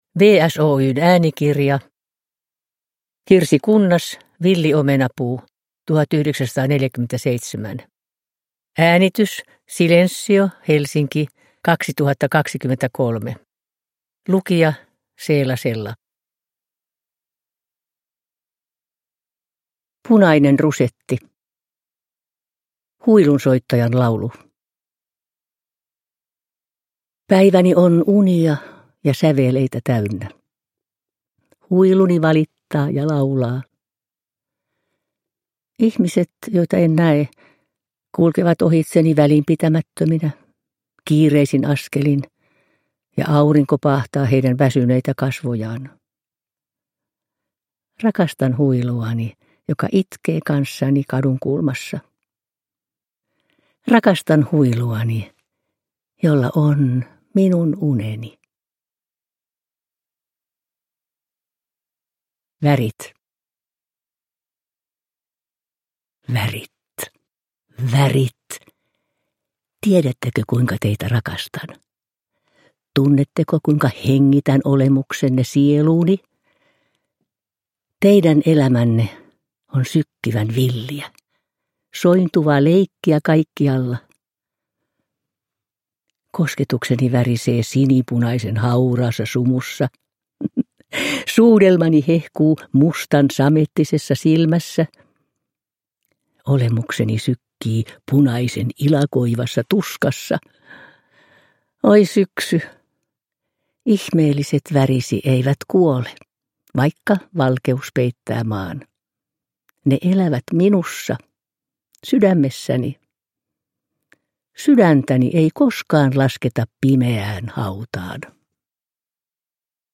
Villiomenapuu – Ljudbok
Uppläsare: Seela Sella